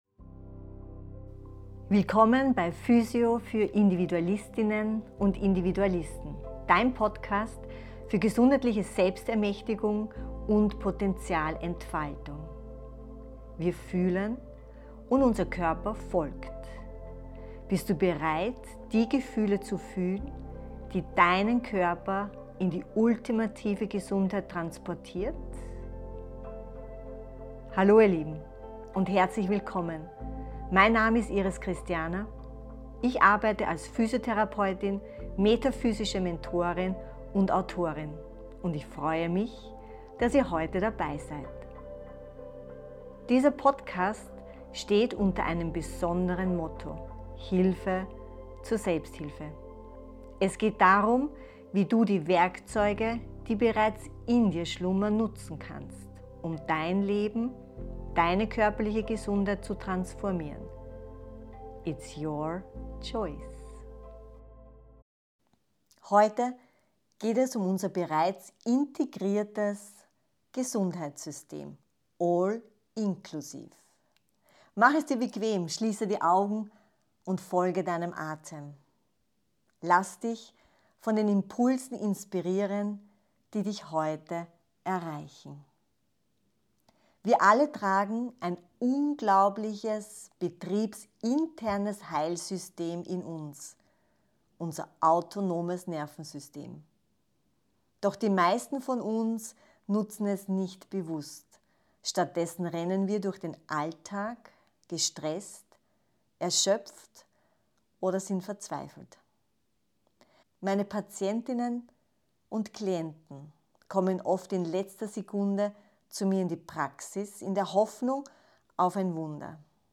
Die Meditationsreihe Hang Loose – Leere deine Festplatte hilft dir dabei, dein autonomes Heilsystem zu aktivieren und dein Wohlbefinden nachhaltig zu verändern.